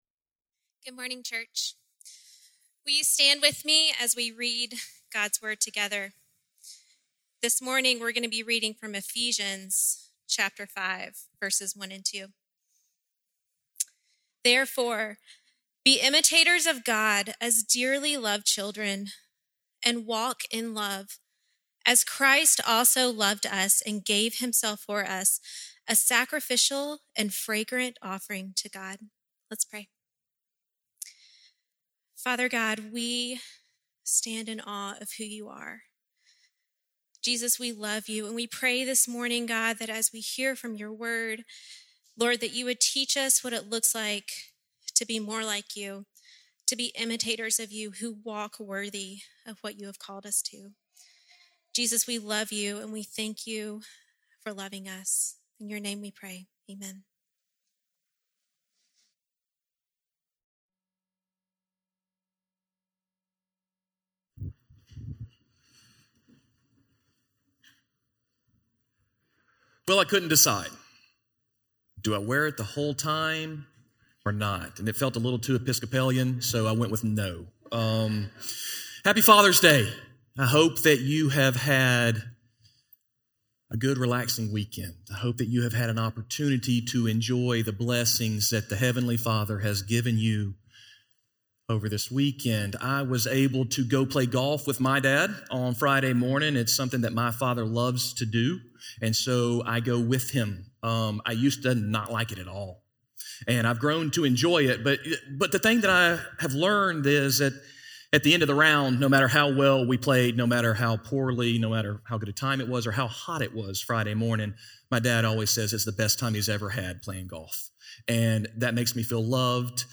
Imitate Christ - Sermon - Lockeland Springs